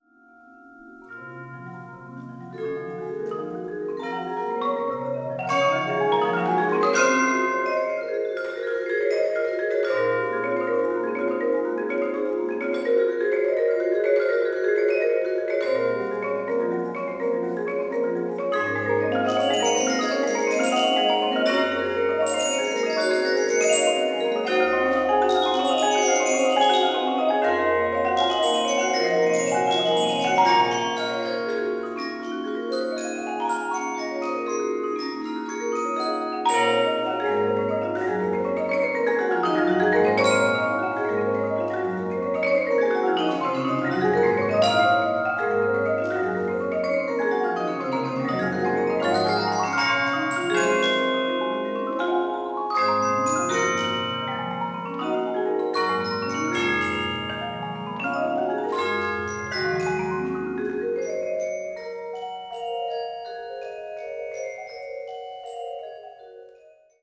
Tetrahedral Ambisonic Microphone
Recorded February 23, 2010, in the Bates Recital Hall at the Butler School of Music of the University of Texas at Austin.